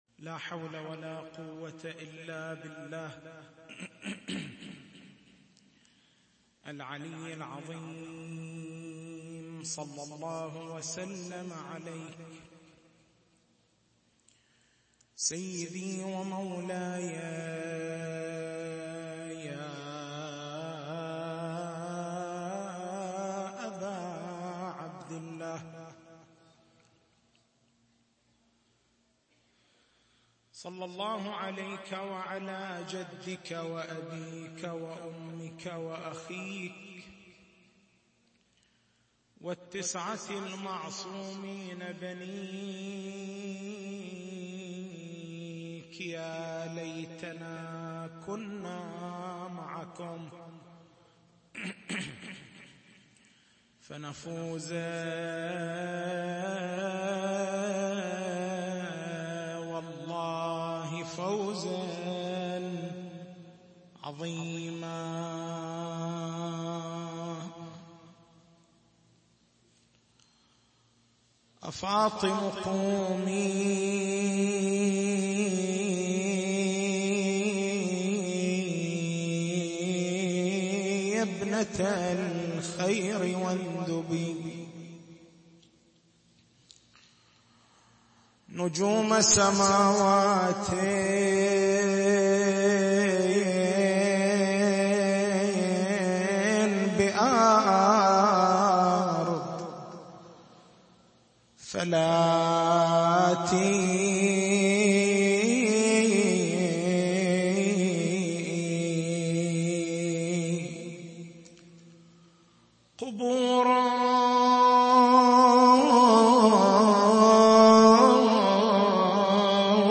تاريخ المحاضرة: 01/01/1438